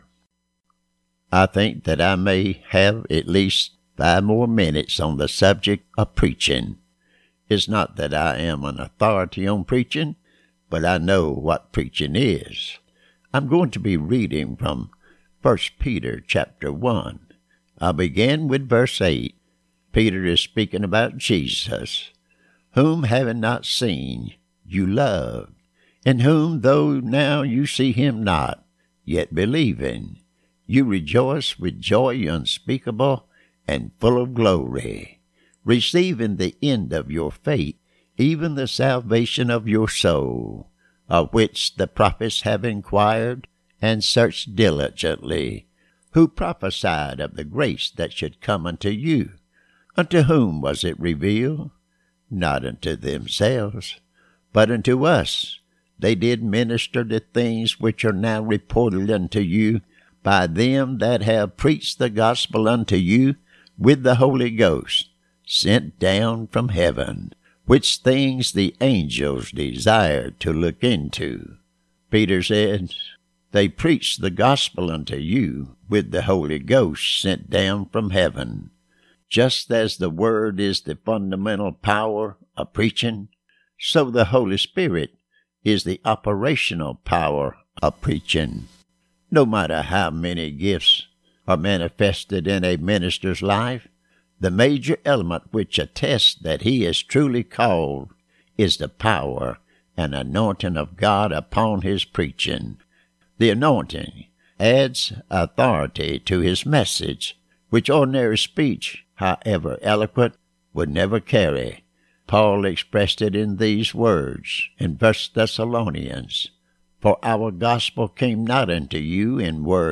Recent Sermons